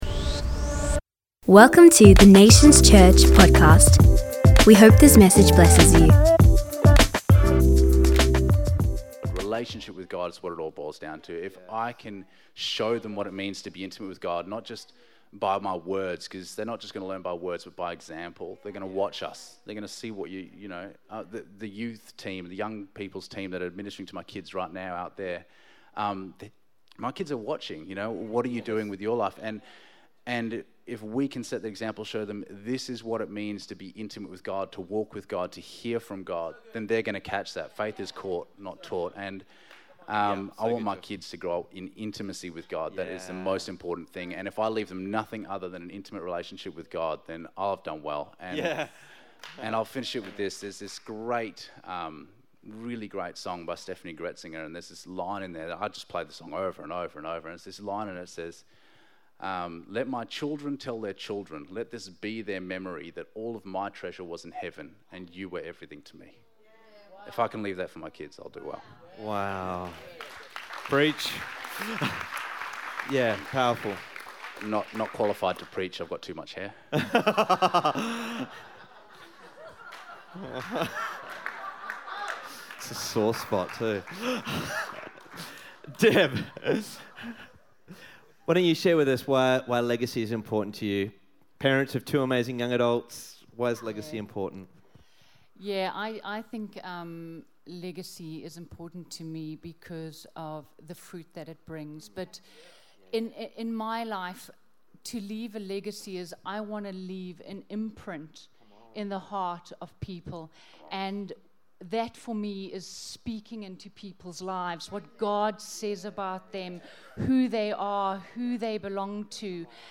Generational Legacy: an inspirational discussion from Myaree's 4pm service.